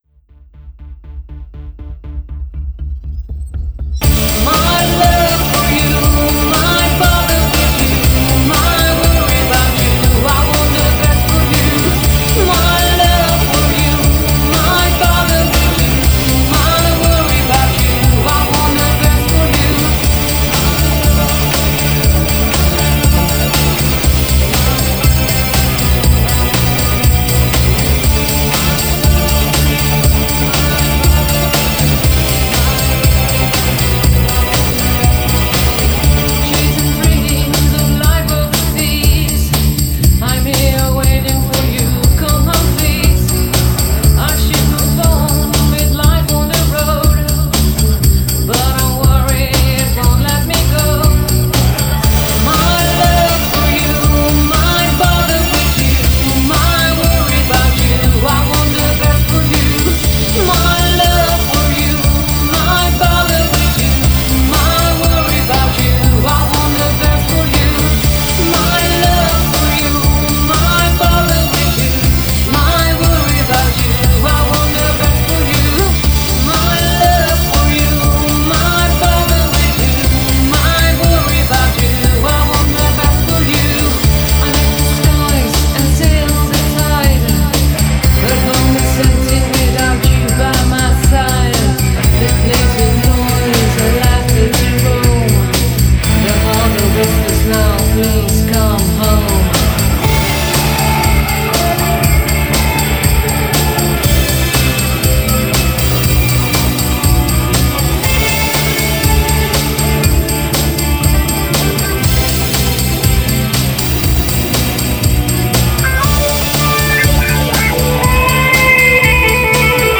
'Cathedral Pop' track
Vocals
Guitar / Bass
Keyboard / Programming